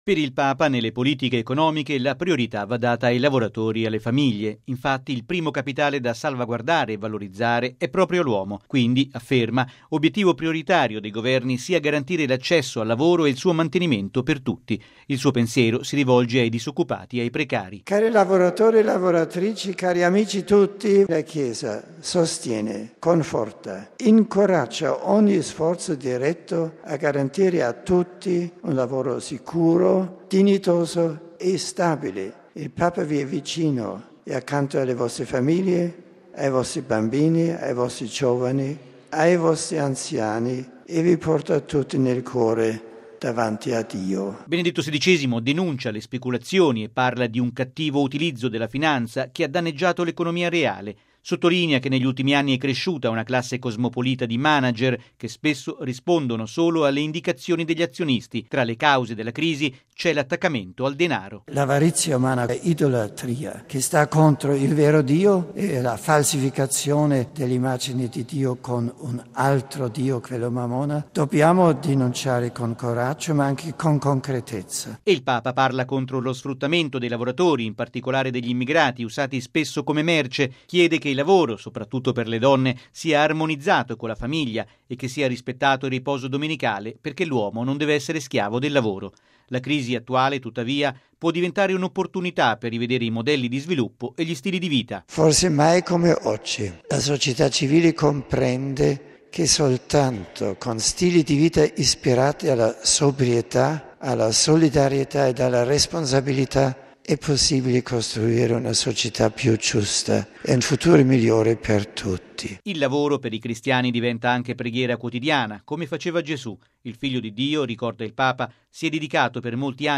(Discorso ai partecipanti al pellegrinaggio della diocesi di Terni, 26 marzo 2011)
(Incontro con il clero romano, 26 febbraio 2009)
(Angelus del 19 marzo 2006)